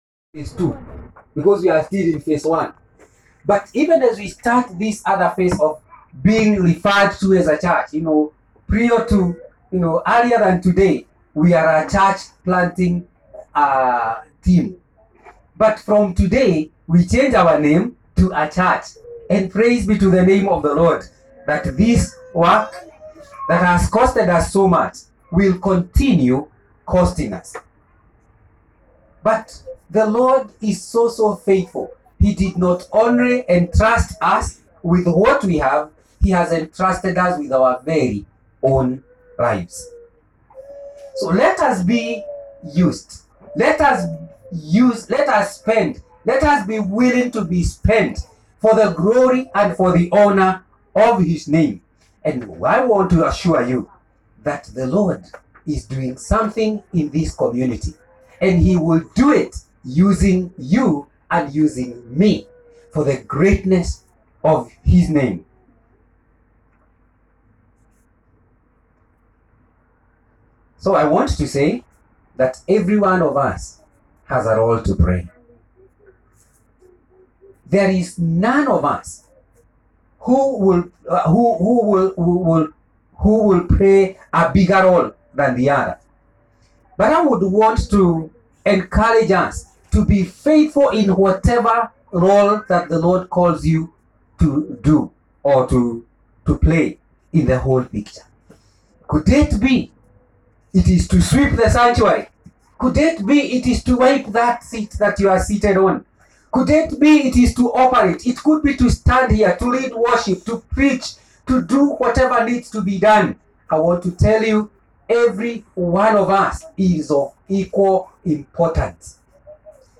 Gracehill Northlands 1st Sermon